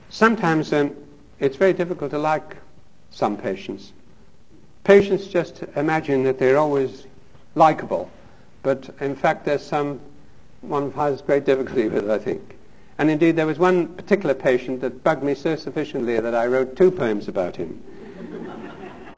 Commentary
Dannie_Abse_commentary_2.m4a